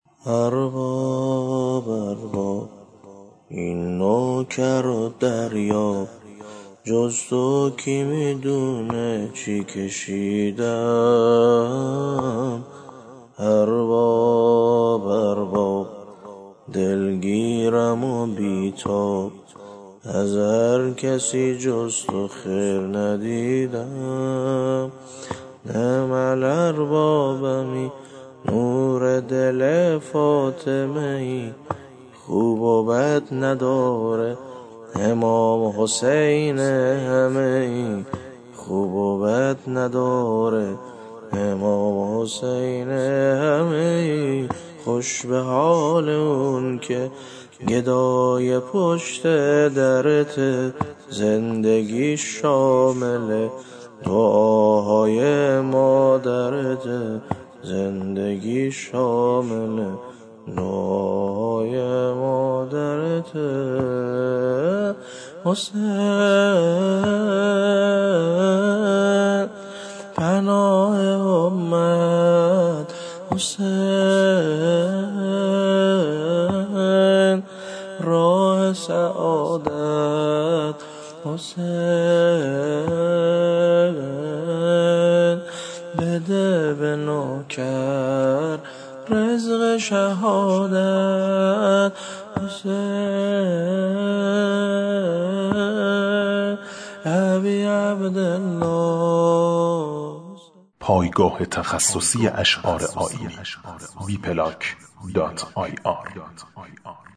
شبهای زیارتی